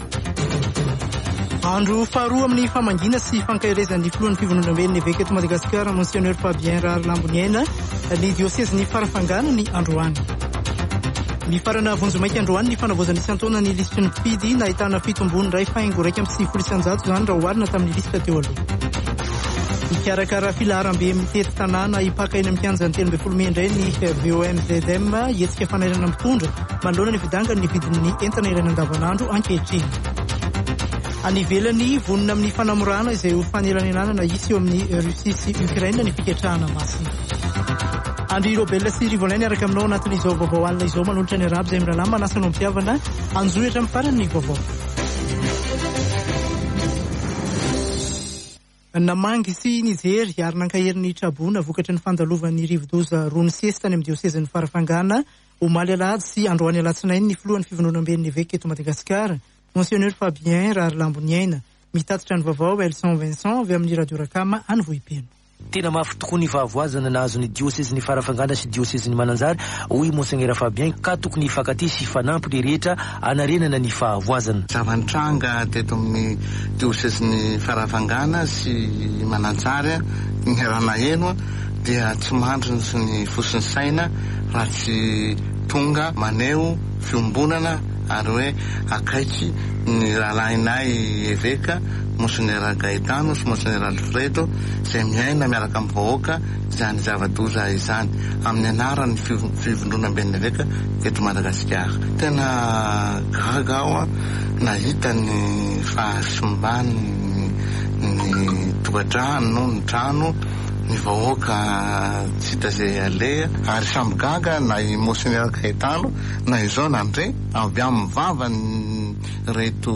[Vaovao hariva] Alatsinainy 28 febroary 2022